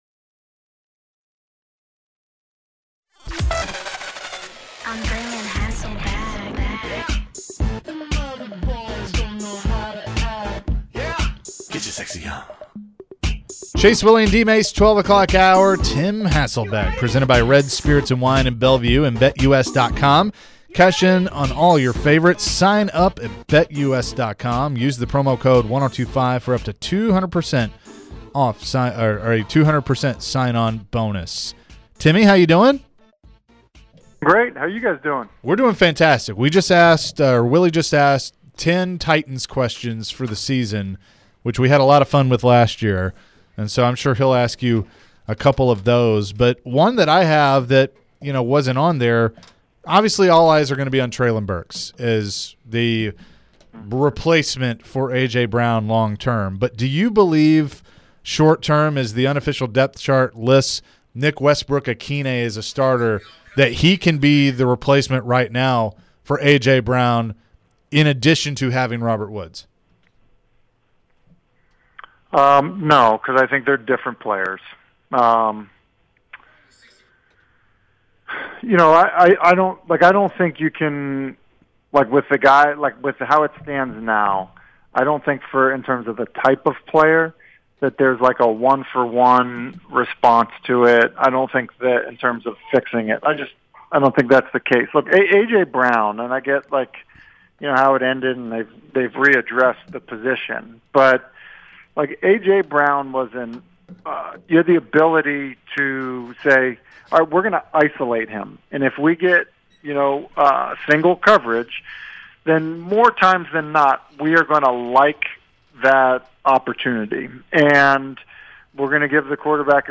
Tim Hasselbeck Full Interview (09-06-22)